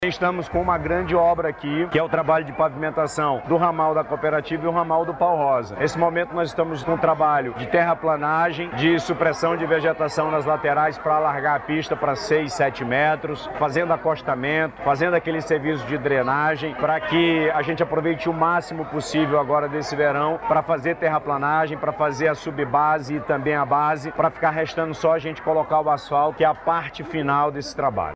Durante uma vistoria, na manhã dessa sexta-feira 01/08, o governador do Amazonas, Wilson Lima, disse que os trabalhos devem avançar bastante neste período de Verão Amazônico.
SONORA-PAVIMENTACAO-RAMAIS-.mp3